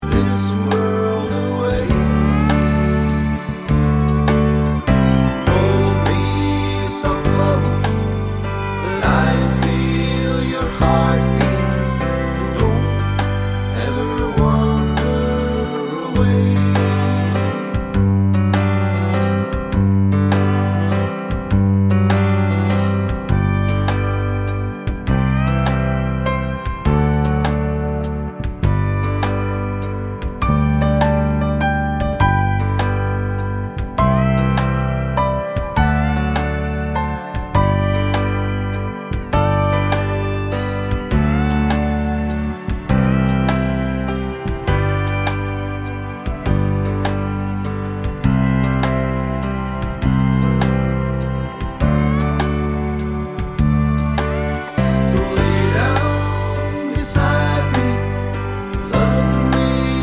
no Backing Vocals Country (Male) 2:53 Buy £1.50